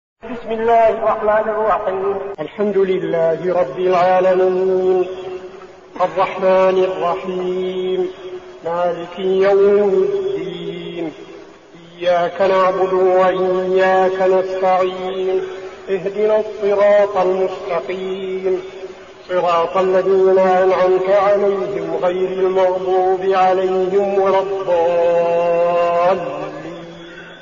المكان: المسجد النبوي الشيخ: فضيلة الشيخ عبدالعزيز بن صالح فضيلة الشيخ عبدالعزيز بن صالح الفاتحة